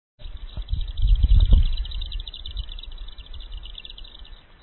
鳴 き 声：ジュクジュクジュクジュクと囀り飛翔をする。
鳴き声１